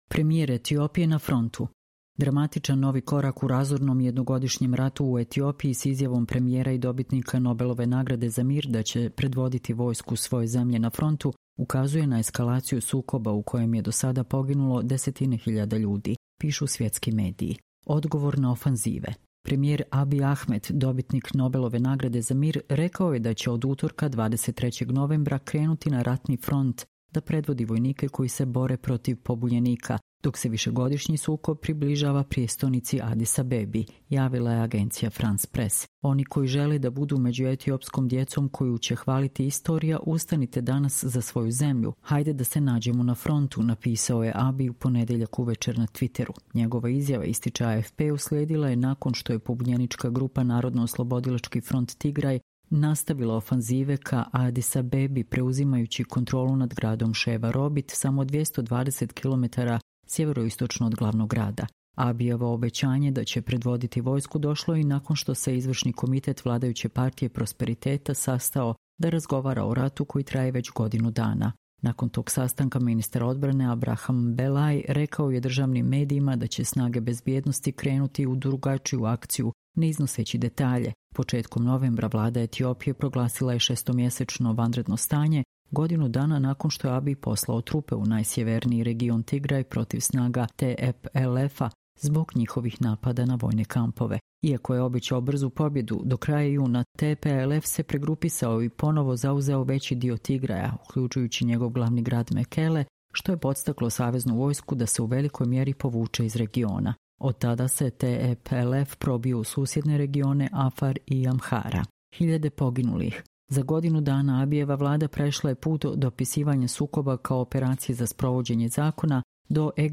Čitamo vam: Premijer Etiopije ‘na frontu’